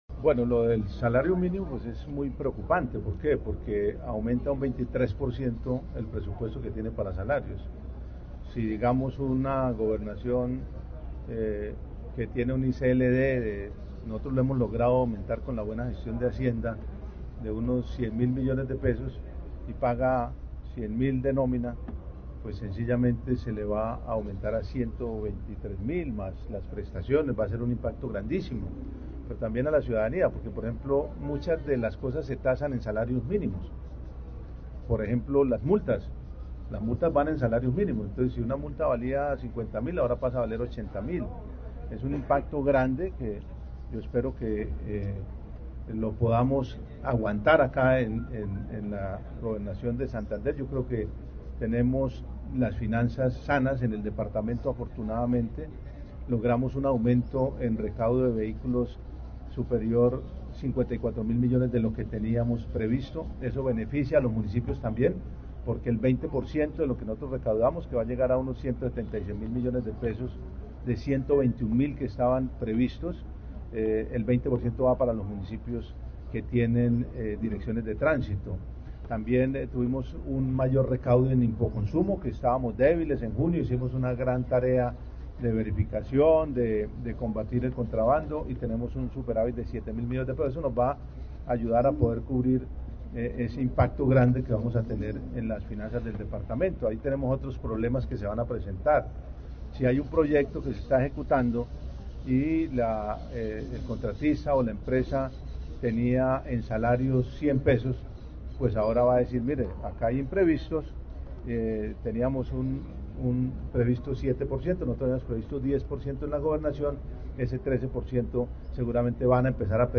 Juvenal Díaz Mateus, gobernador de Santander